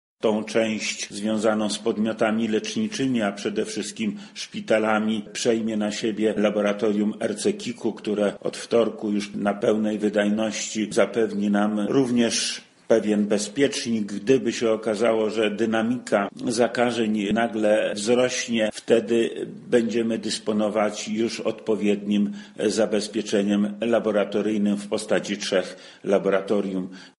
Jak twierdzi wojewoda lubelski Lech Sprawka, taki rozkład pracy pozwoli usprawnić działanie służb medycznych i walkę z koronawirusem: